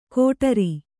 ♪ kōṭari